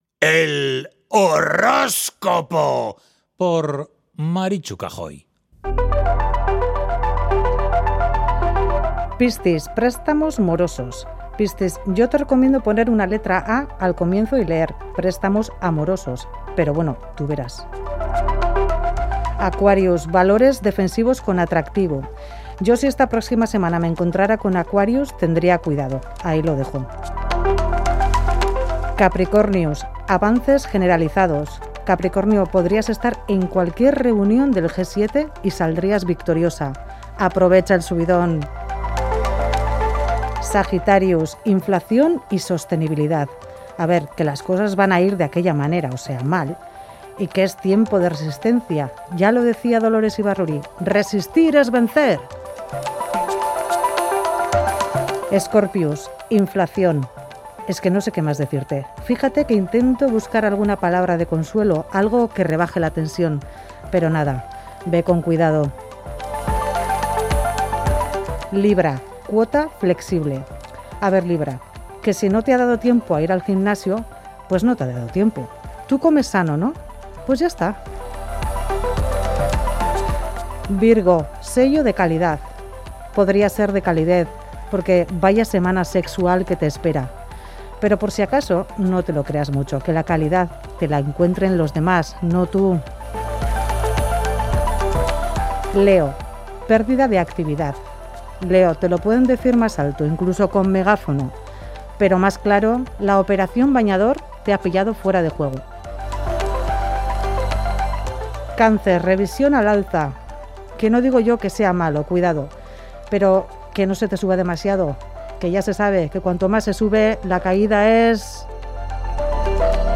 con mucho humor, ironía y algo de mala uva.